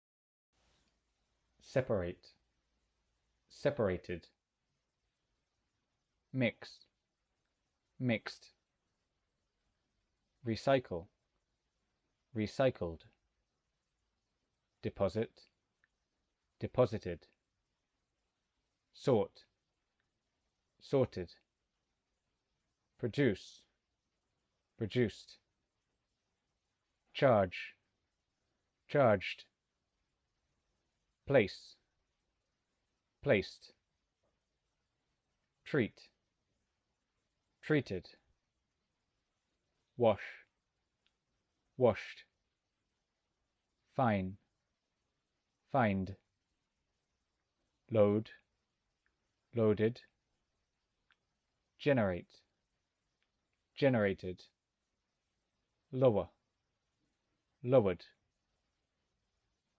– Either: prepare the audio, or be prepared to pronounce the ‘ed’ endings for your students.
Infinitive vs Participle MP3